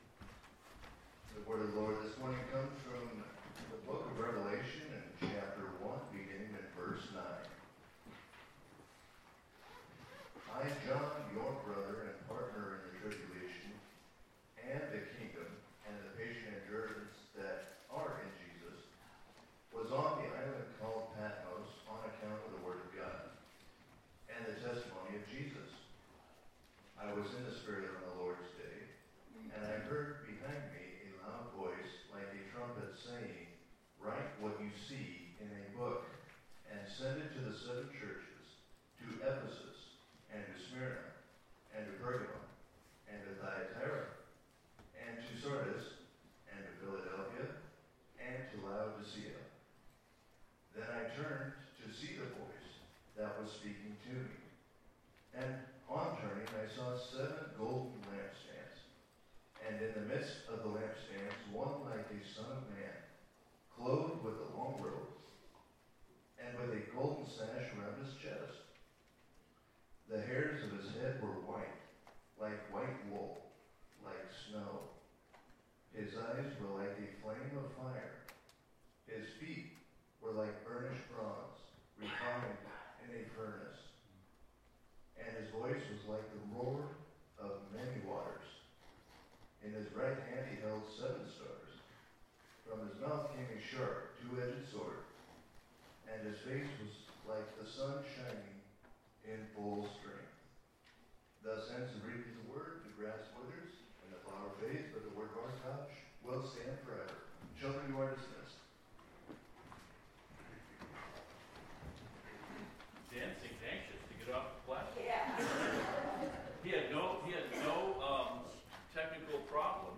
Preacher: Pastor